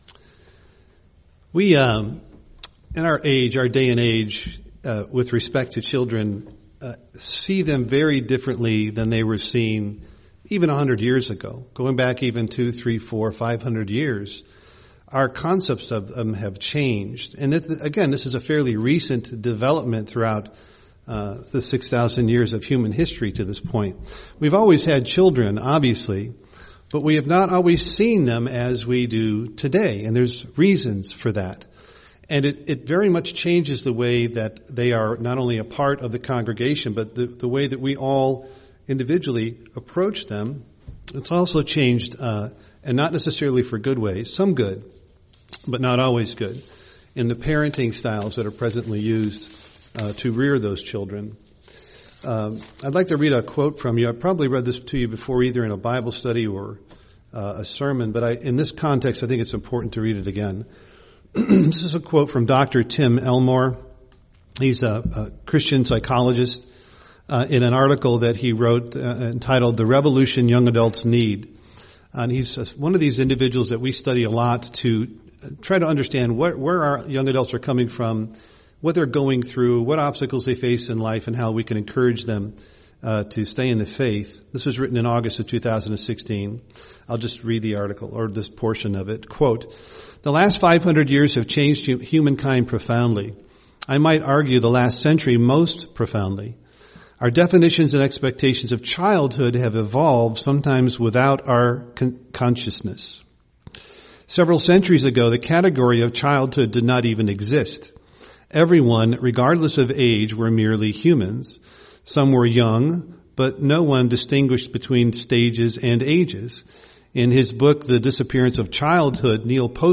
UCG Sermon Children Studying the bible?